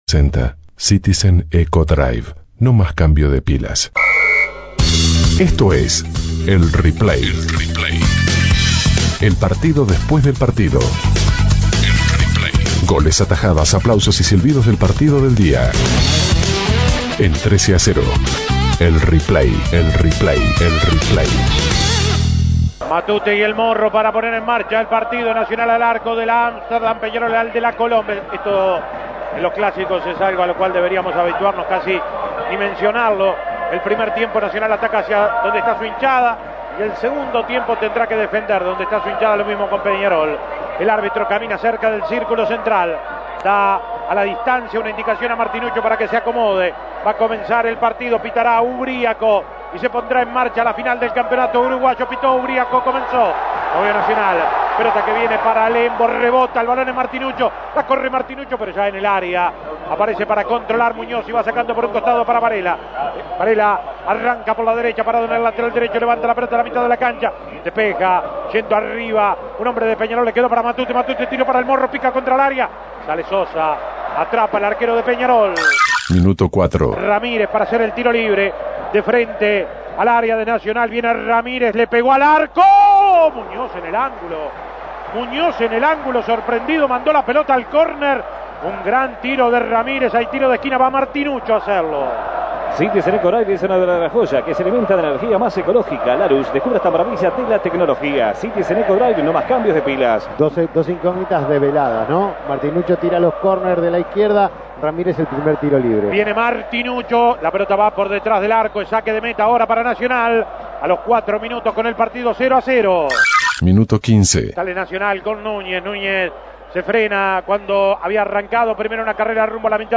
Escuche los goles y las mejores jugadas del clásico entre Peñarol y Nacional. El partido terminó 1-1 y los aurinegros lograron el campeonato uruguayo 2009-2010.
Goles y comentarios El Replay de la Segunda Final Imprimir A- A A+ Escuche los goles y las mejores jugadas del clásico entre Peñarol y Nacional.